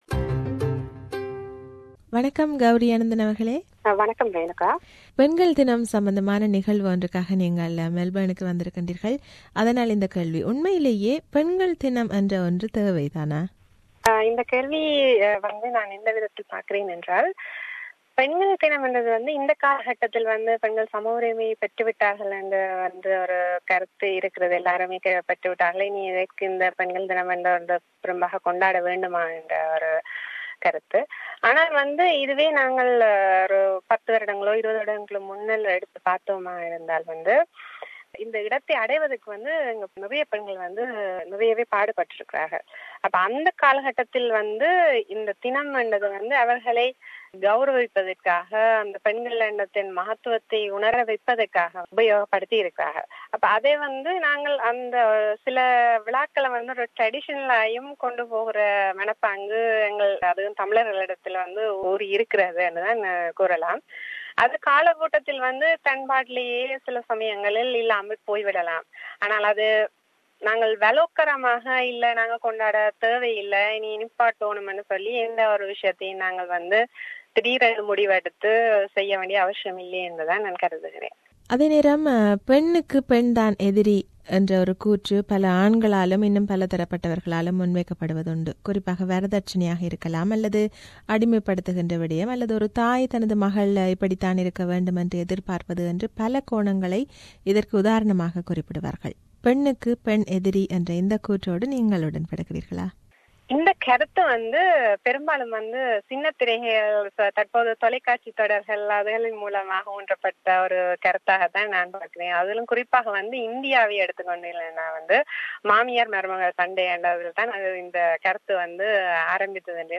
This is an interview with her.